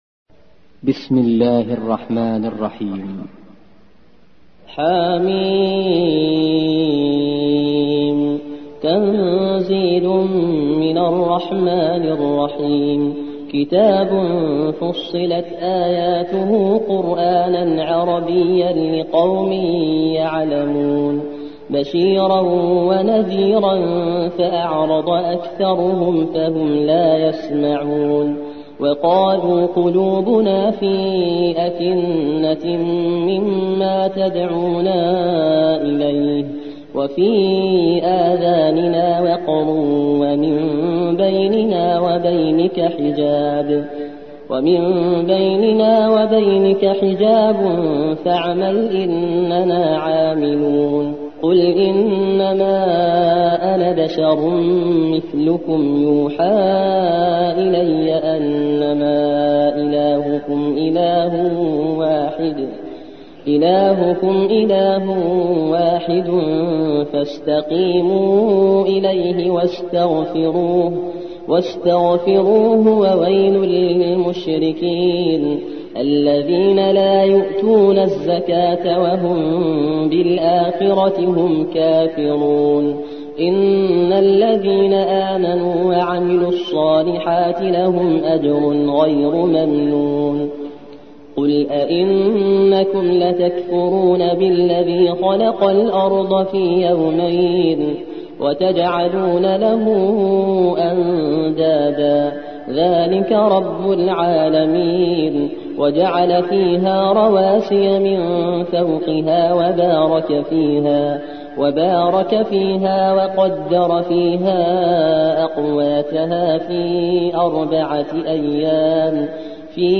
41. سورة فصلت / القارئ